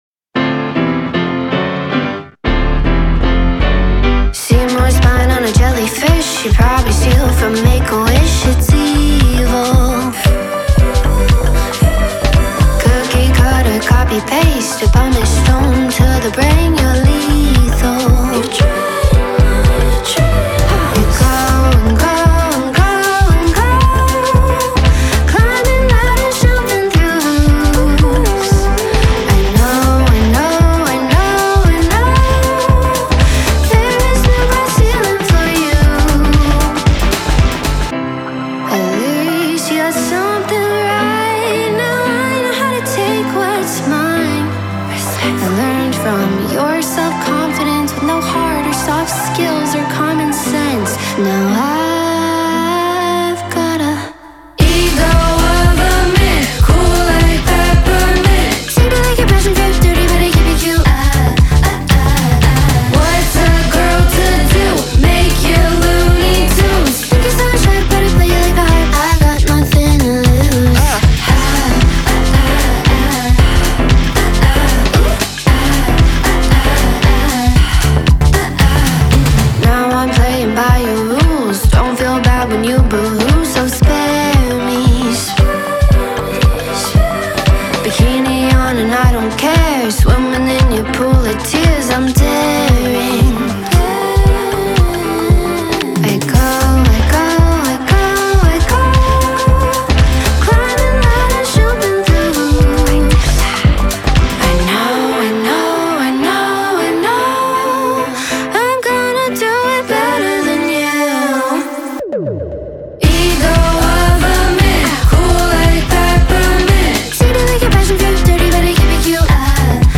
BPM115-115
Audio QualityPerfect (High Quality)
Alternative Pop song for StepMania, ITGmania, Project Outfox
Full Length Song (not arcade length cut)